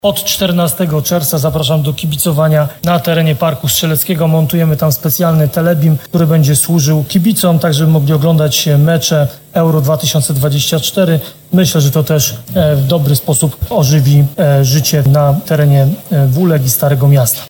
– mówi Ludomir Handzel, prezydent Nowego Sącza.